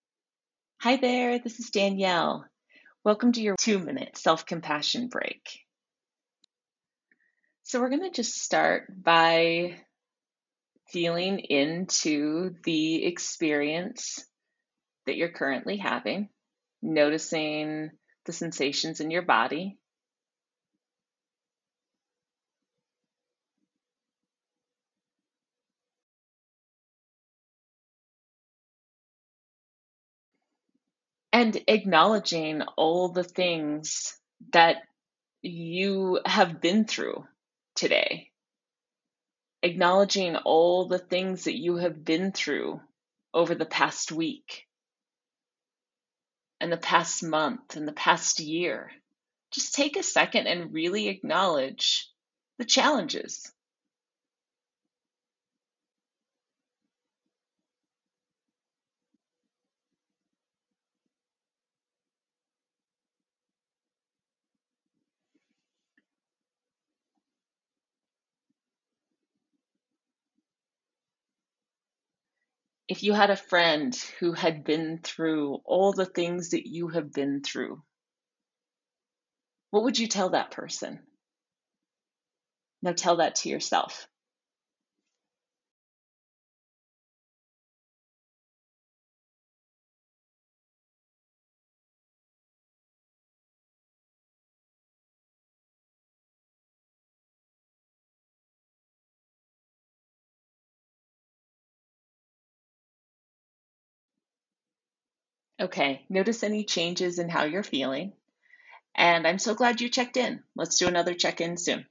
Take a few minutes to give yourself a break and engage in a quick guided meditation.
They are brief guided meditations designed to cultivate self-compassion and bring kindness and understanding to oneself.